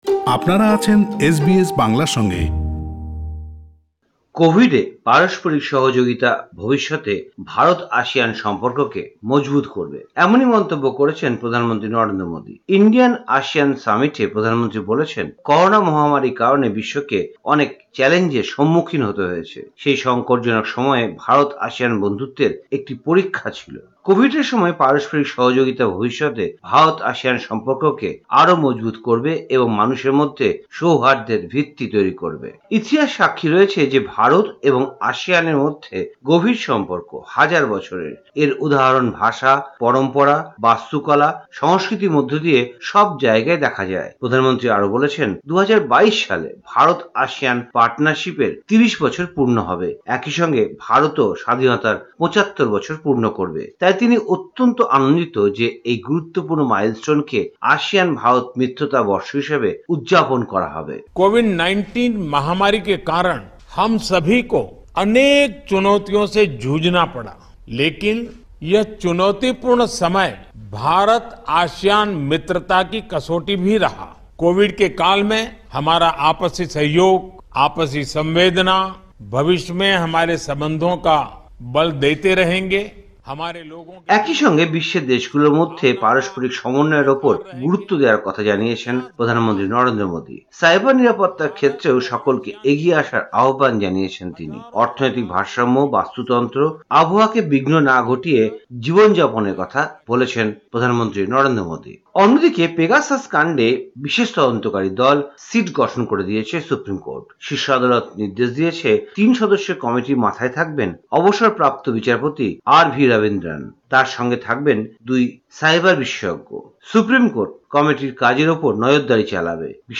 ভারতীয় সংবাদ: ১ নভেম্বর ২০২১
কলকাতা থেকে